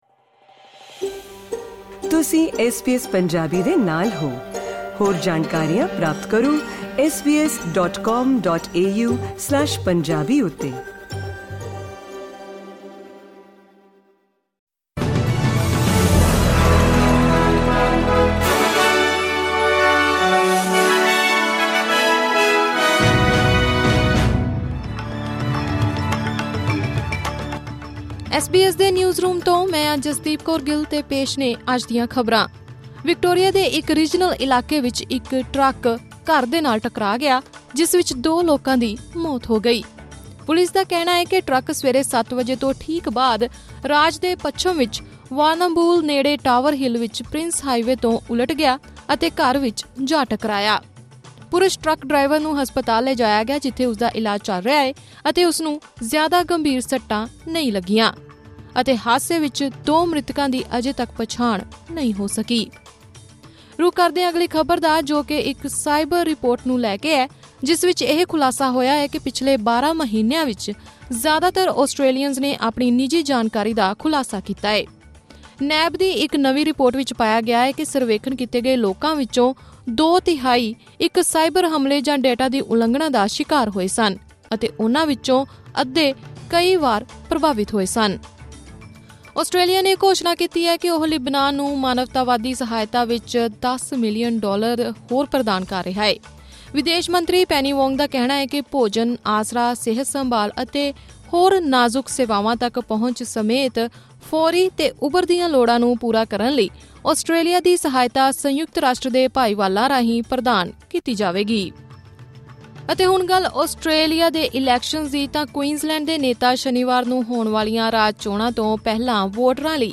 ਐਸ ਬੀ ਐਸ ਪੰਜਾਬੀ ਤੋਂ ਆਸਟ੍ਰੇਲੀਆ ਦੀਆਂ ਮੁੱਖ ਖ਼ਬਰਾਂ: 25 ਅਕਤੂਬਰ 2024